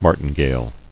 [mar·tin·gale]